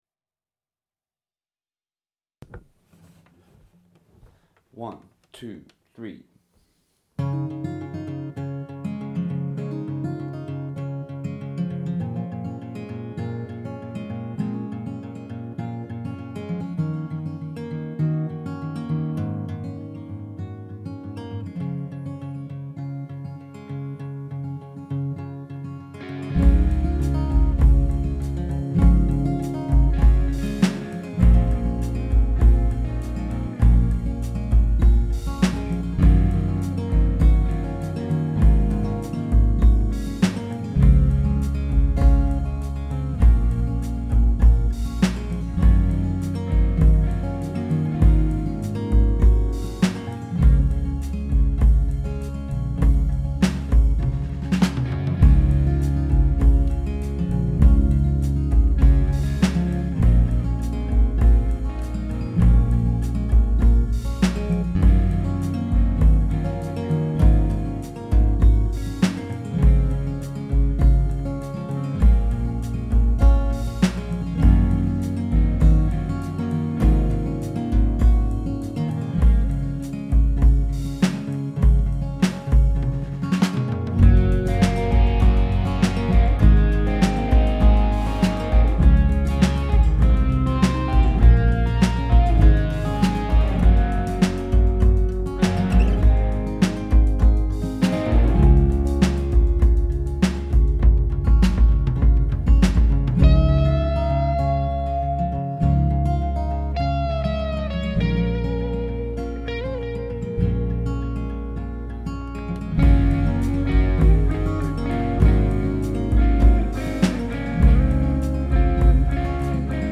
So here is one of the half baked ideas I have been working with. It’s a bit soppy and sentimental, but I like it for some reason.